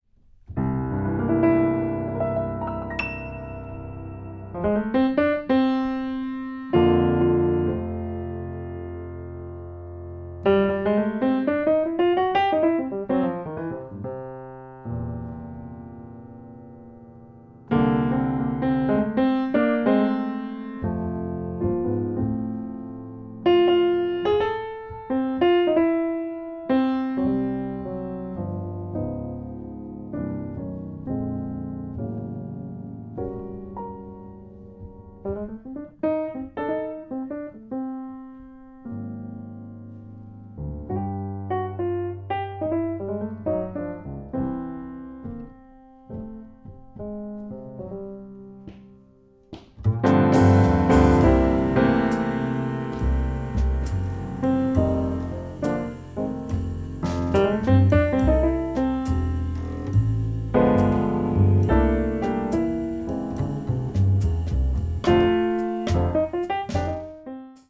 とても息の合ったアンサンブルを聴かせているのだ。
1日目のピアノトリオの録音が終わったところに、